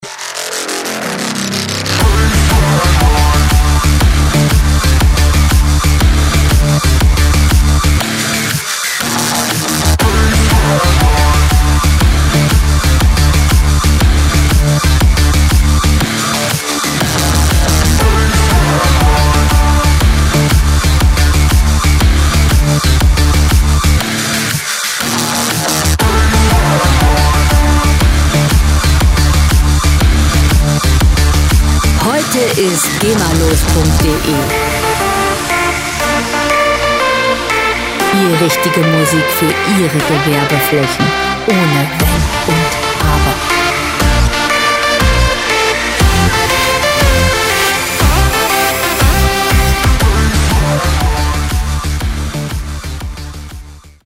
• Dark Electro